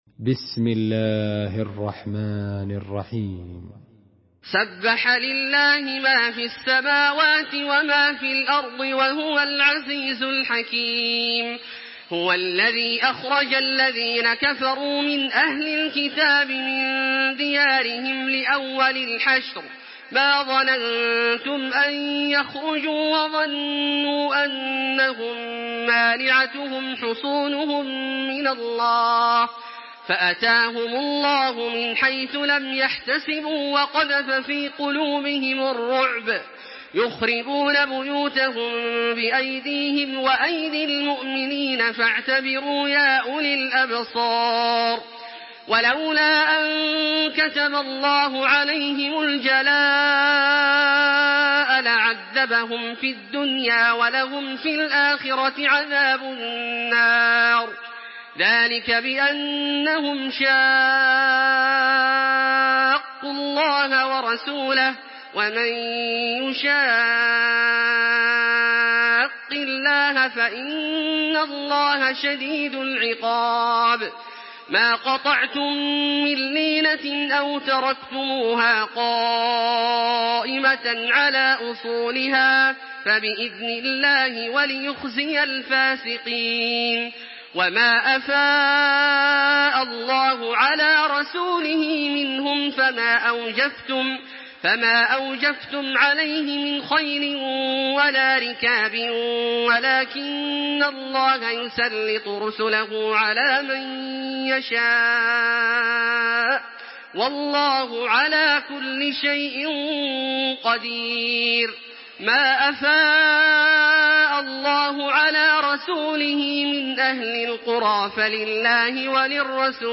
Surah আল-হাশর MP3 by Makkah Taraweeh 1426 in Hafs An Asim narration.
Murattal Hafs An Asim